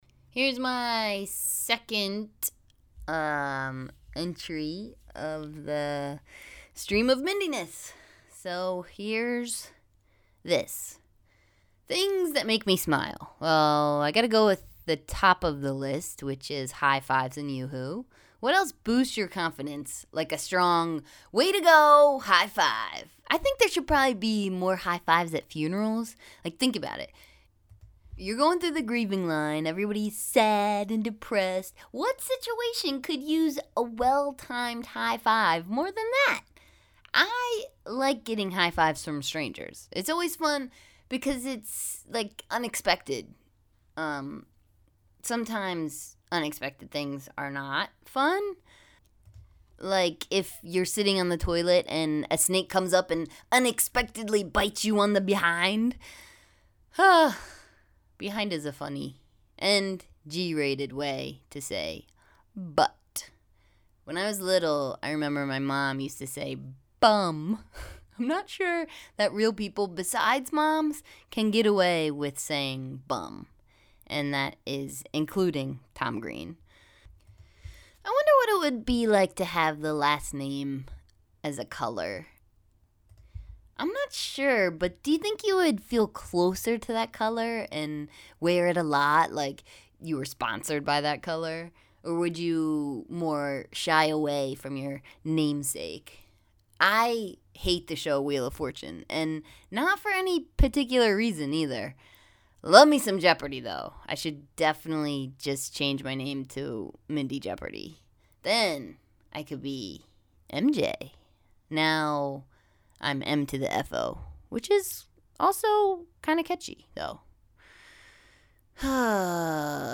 PRESS PLAY TO HEAR ME READ THIS BLOG TO YOU!